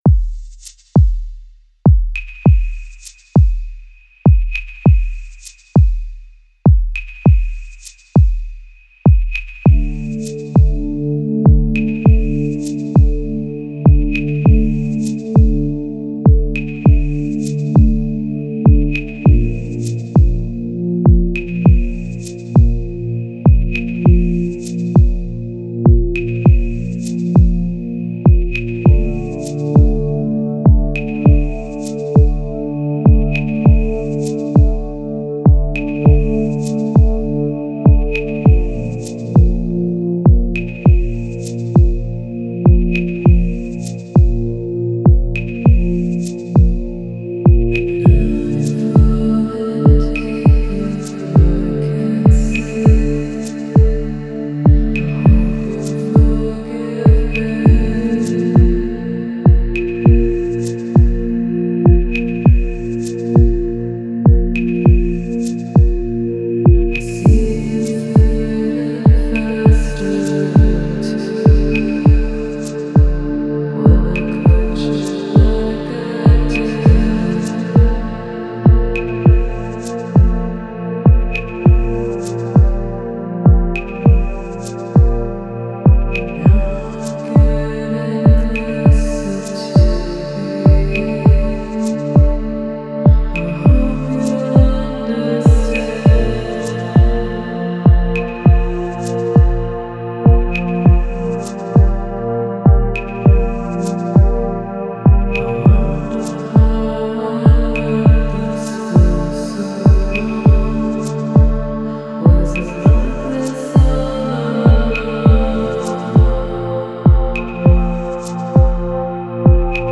J’entends une ligne de basse étrangement mélodique.